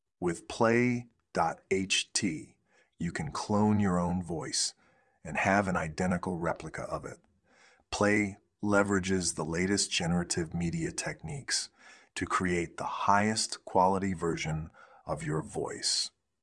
Add some more sample voice
Dwayne-Johnson-sample.wav